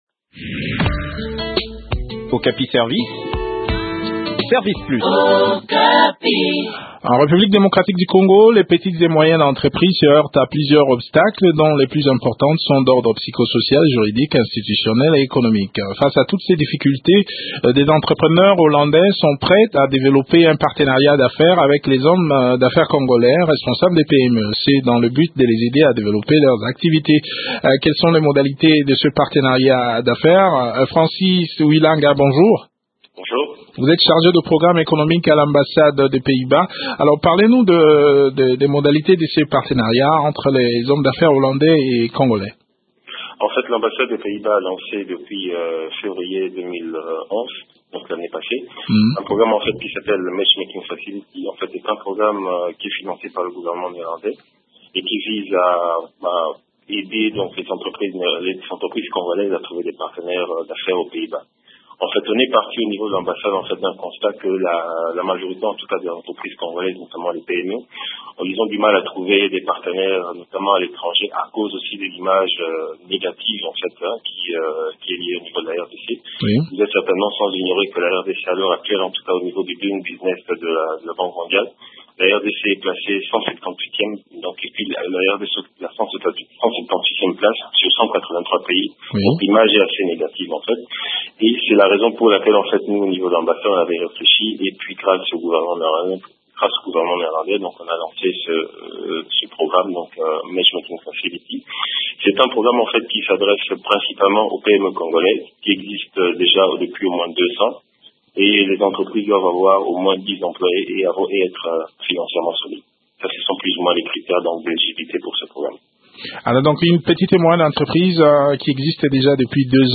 Le point sur les préalables à remplir avant de postuler dans cet entretien